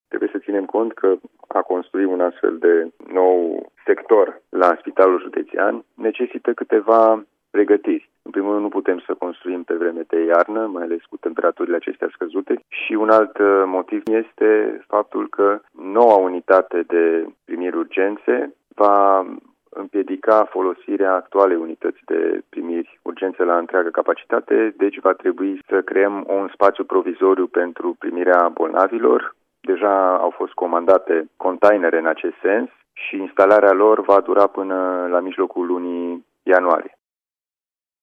Unitatea de Primiri Urgențe va fi extinsă, dar, pentru derularea lucrărilor trebuie montate containere în care să fie mutată activitatea medicală, a precizat, la Radio Timişoara, preşedintele Consiliului Judeţean Timiş, Alin Nica.
Alin-Nica-1.mp3